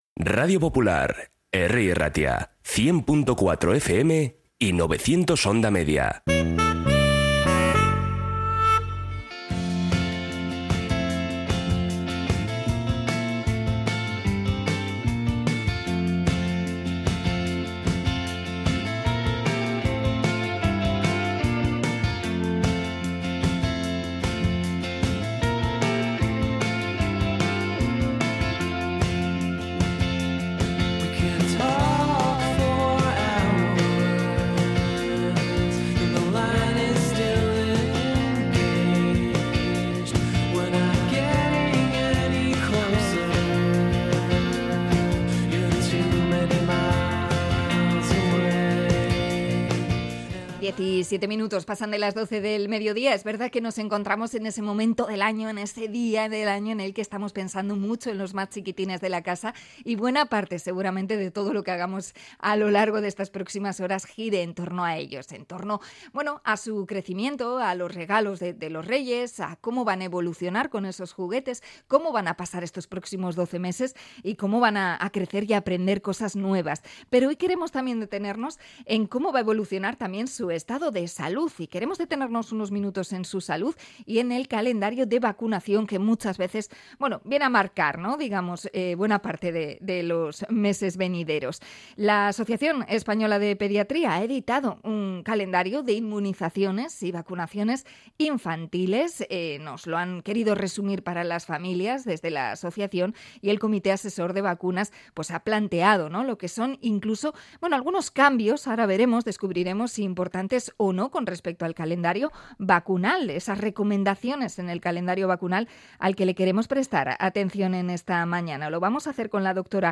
Entrevista a pediatra por el calendario vacunal propuesto para este año